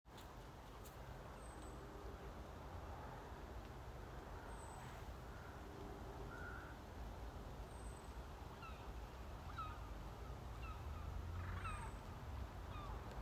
Bird Aves sp., Aves sp.
Administratīvā teritorijaĀdažu novads
StatusAgitated behaviour or anxiety calls from adults